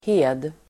Ladda ner uttalet
hed substantiv, moor , heath Uttal: [he:d] Böjningar: heden, hedar Definition: trädlös slättmark (a tract of treeless, often flat country) Sammansättningar: hed|mark (heathland), fjällhed (alpine heath)